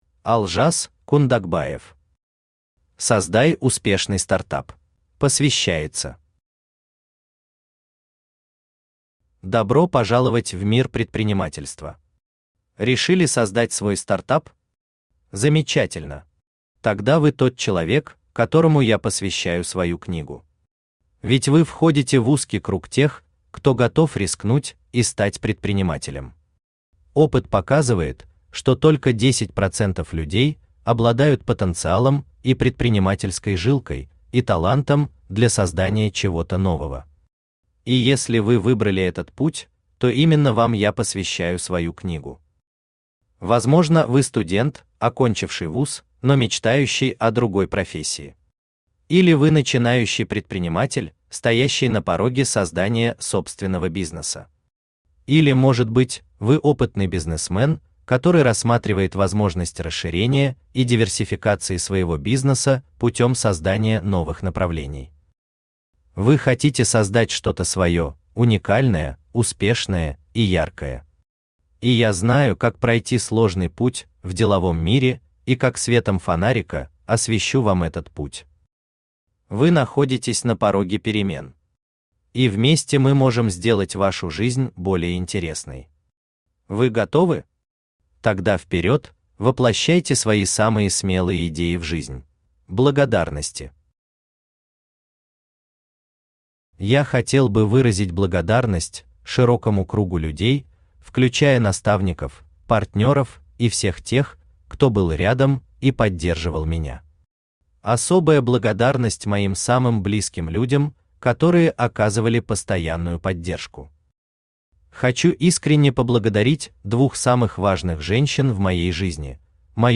Aудиокнига Создай успешный стартап Автор Олжас Кундакбаев Читает аудиокнигу Авточтец ЛитРес. Прослушать и бесплатно скачать фрагмент аудиокниги